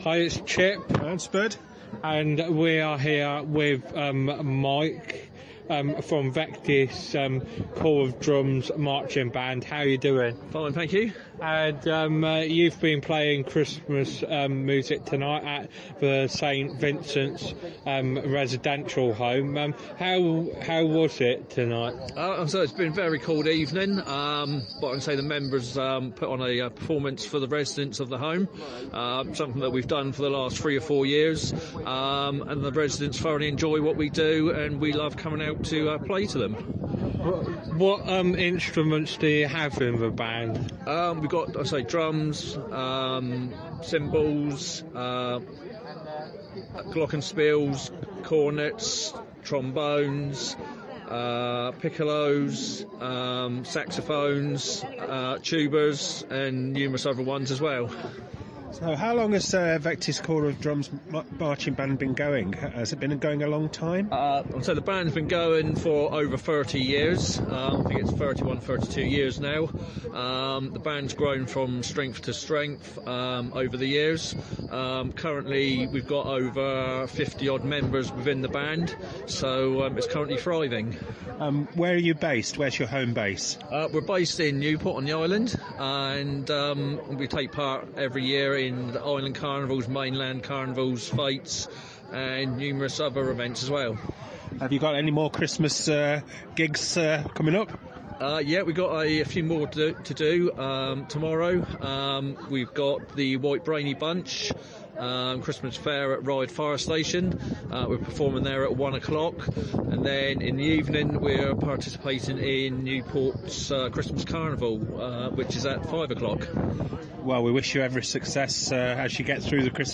St Vincents residential home Christmas lights switch on 2023